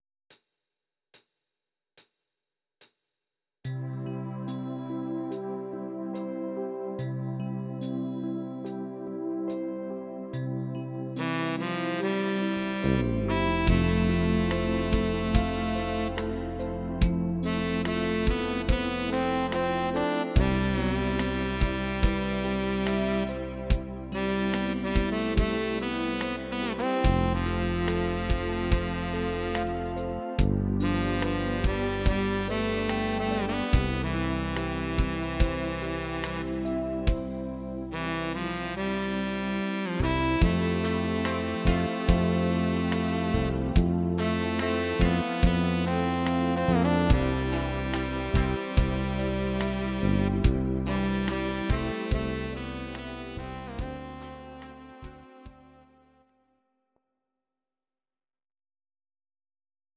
Audio Recordings based on Midi-files
Instrumental, Traditional/Folk